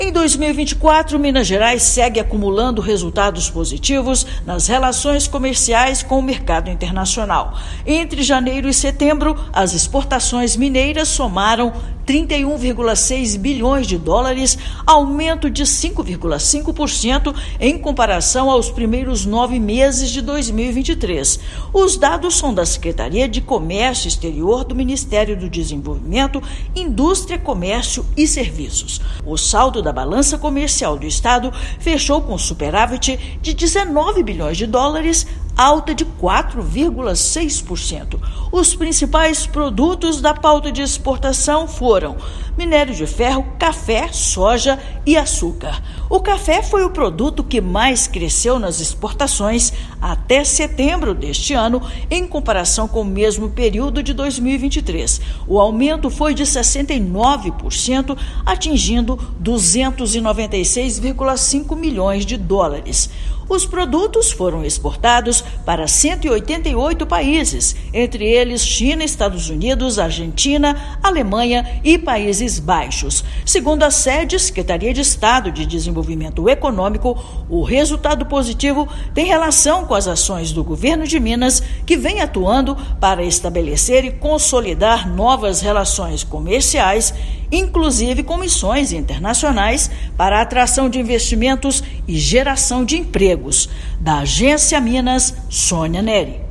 No ano, exportações do estado ao exterior avançaram 5,5%; superávit na balança comercial é de US$ 19,1 bilhões. Ouça matéria de rádio.